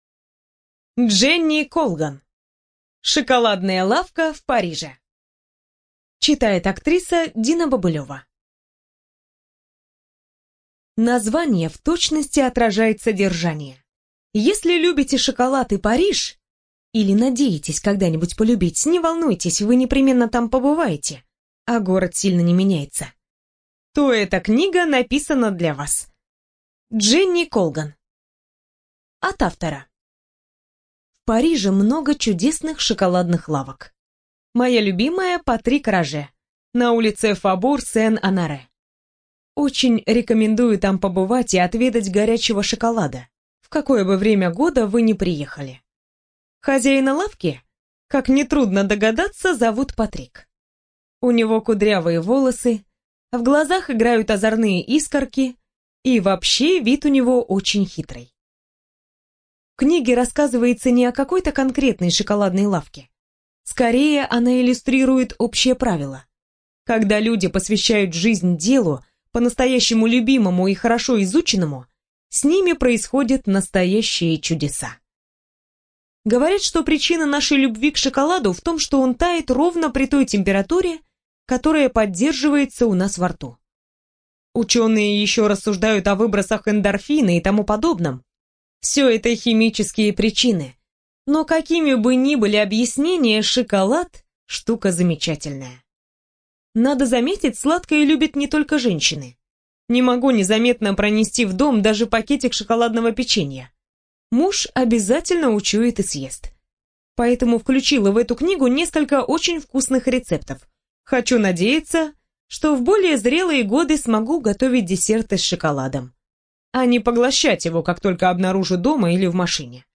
БиблиотекаКниги → Шоколадная лавка в Париже